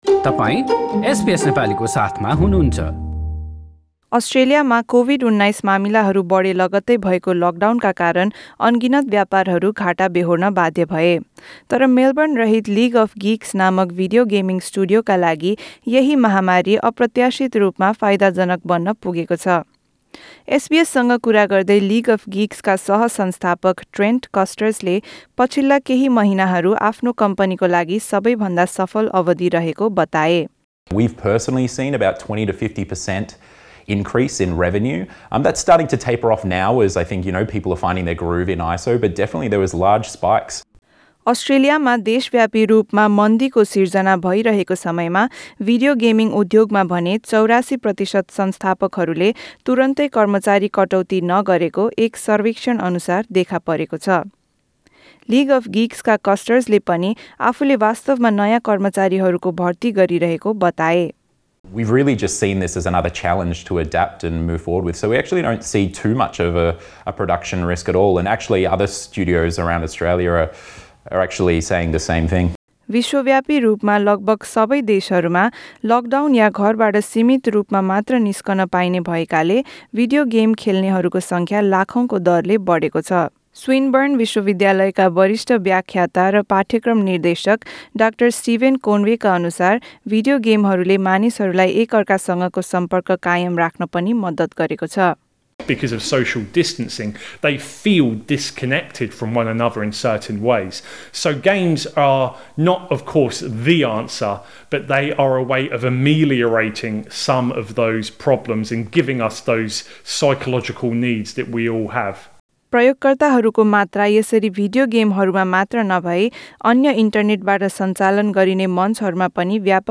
यस बारेमा तयार पारिएको एक रिपोर्ट।